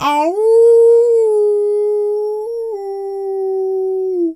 wolf_2_howl_soft_05.wav